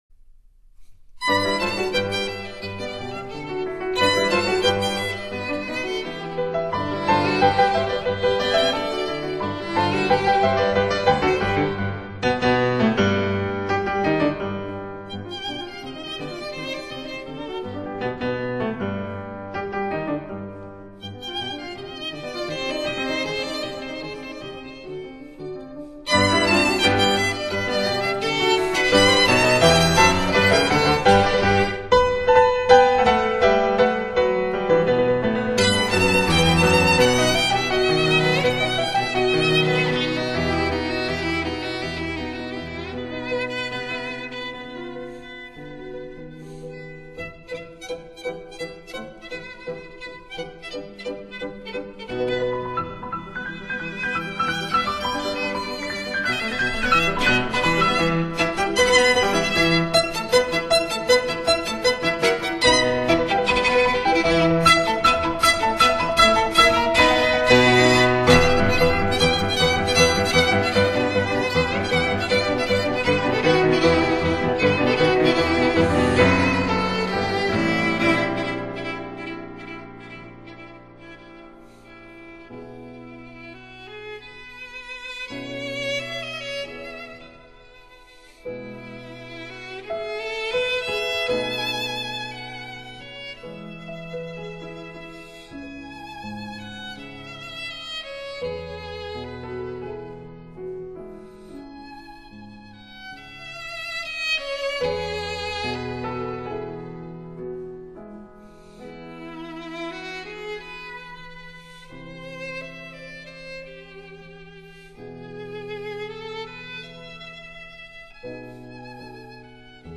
别    名:Works for violin and piano